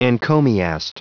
Prononciation du mot encomiast en anglais (fichier audio)
Prononciation du mot : encomiast